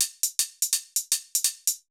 Index of /musicradar/ultimate-hihat-samples/125bpm
UHH_ElectroHatC_125-05.wav